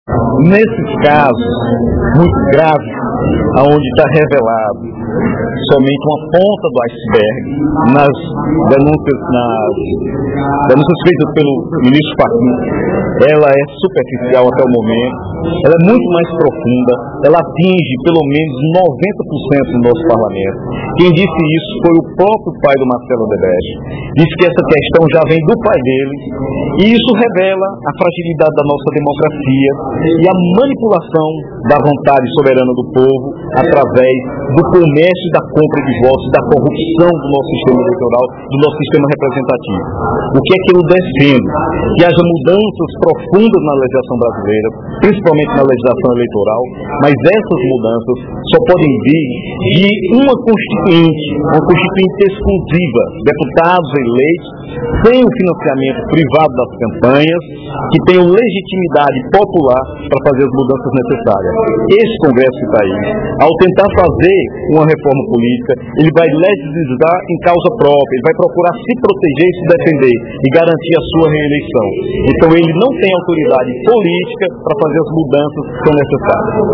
O deputado Dr. Santana (PT) externou preocupação, nesta quarta-feira (12/04), durante o primeiro expediente da sessão plenária da Assembleia Legislativa, com o regime político brasileiro, que, segundo ele, teve a morte decretada.